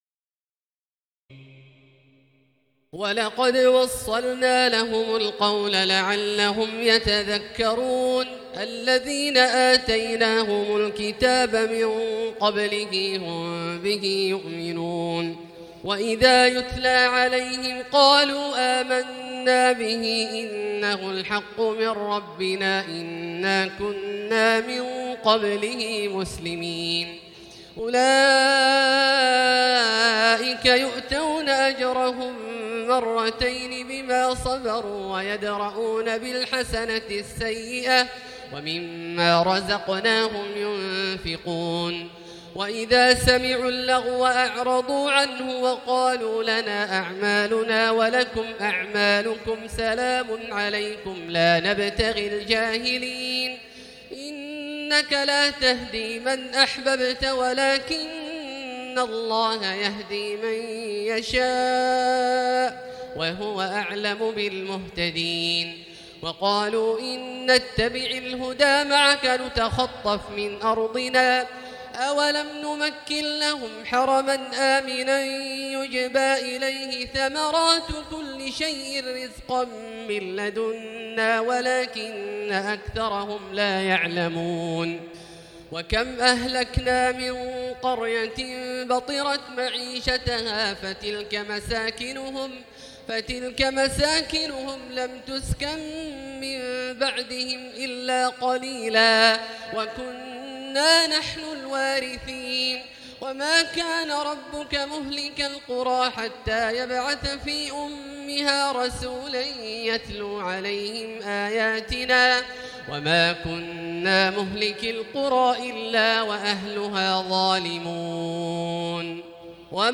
تراويح الليلة التاسعة عشر رمضان 1439هـ من سورتي القصص (51-88) والعنكبوت (1-45) Taraweeh 19 st night Ramadan 1439H from Surah Al-Qasas and Al-Ankaboot > تراويح الحرم المكي عام 1439 🕋 > التراويح - تلاوات الحرمين